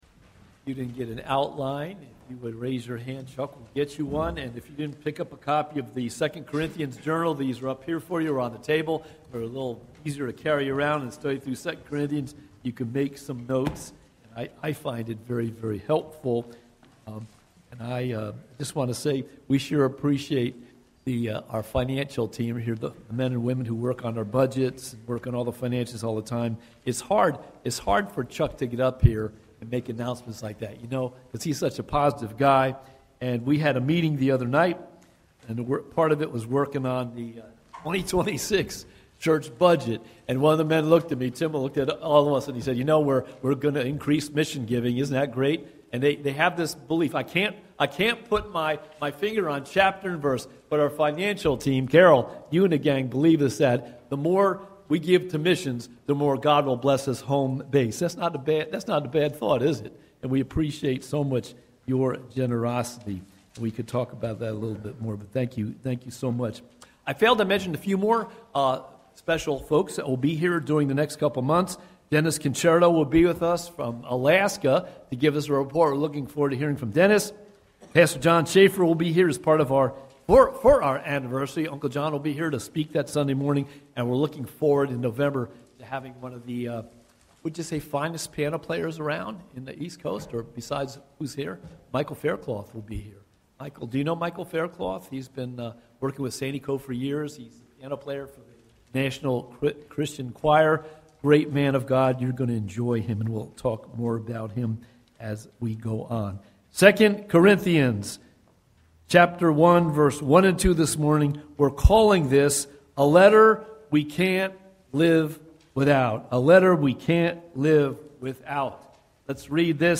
Watch Online Service recorded at 9:45 Sunday morning.